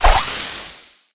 crow_throw_01.ogg